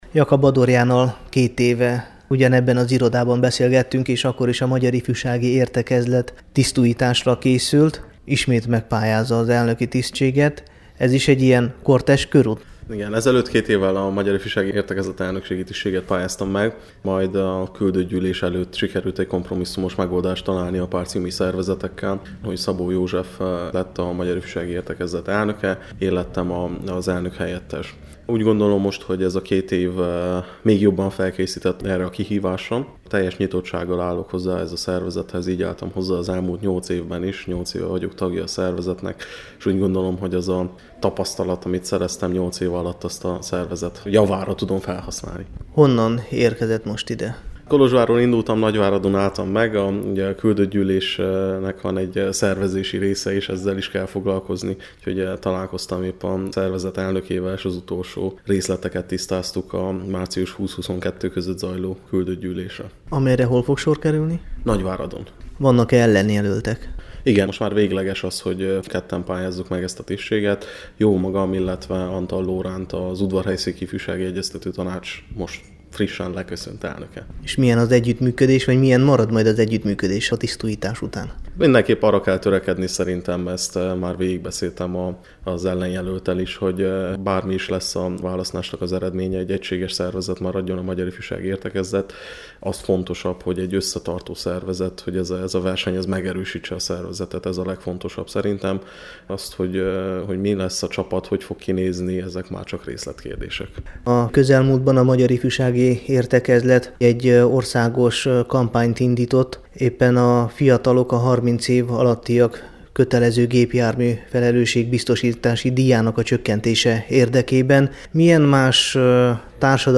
Hallgassa meg az interjút, amely a Temesvári Rádió mai ifjúsági műsora számára készült!